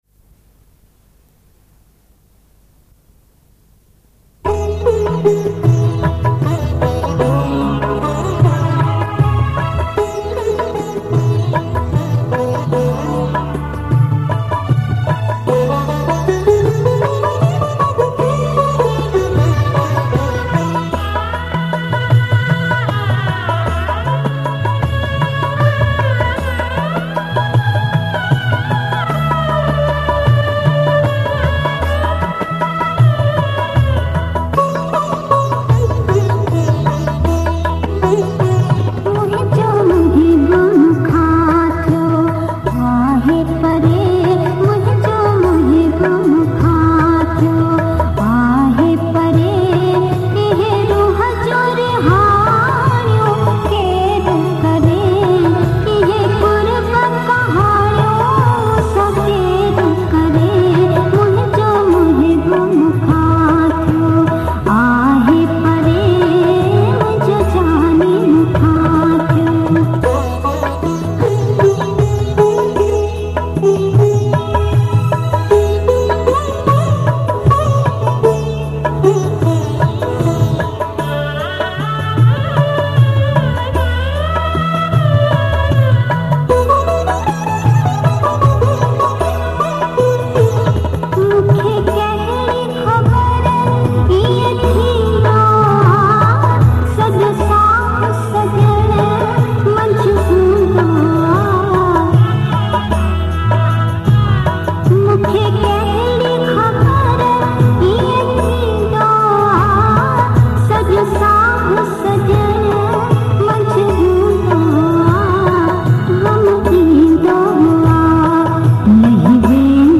Sindhi Kalam, Geet, Qawali, Duet